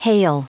Prononciation du mot hale en anglais (fichier audio)
Prononciation du mot : hale